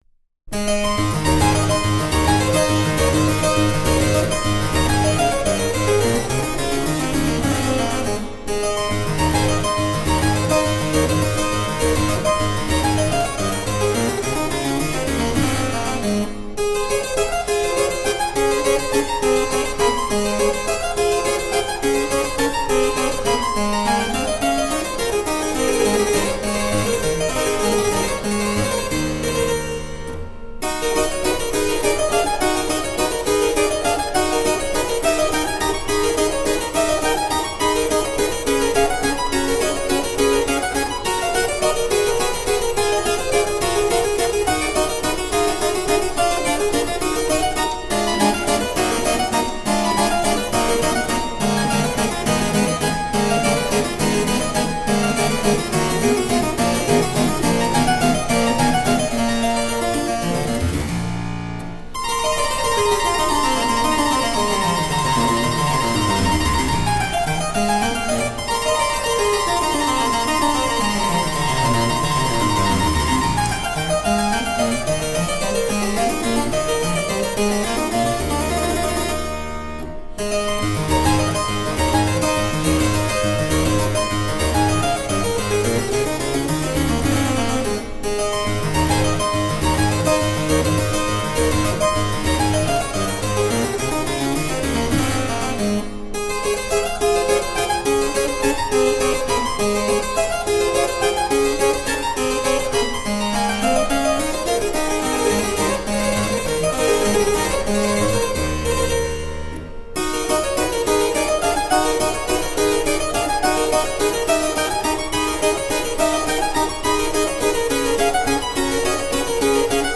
Sonate pour clavecin Kk 114 : Presto